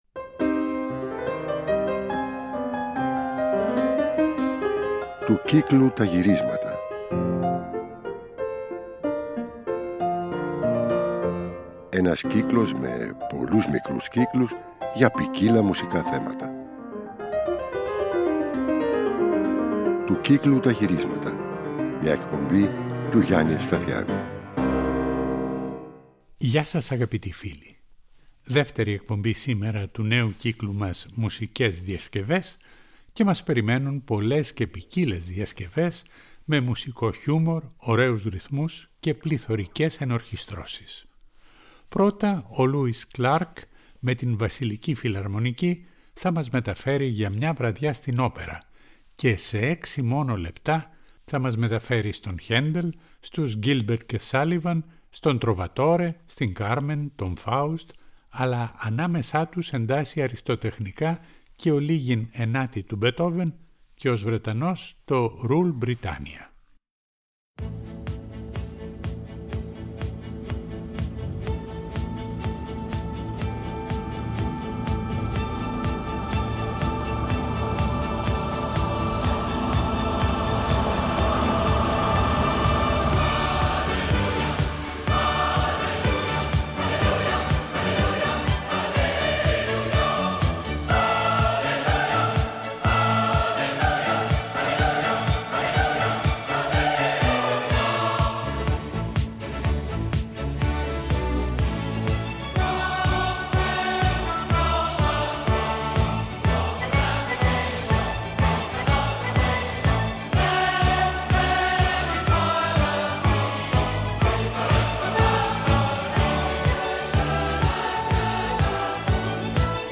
οπερατικές διασκευές
φωνητικό σύνολο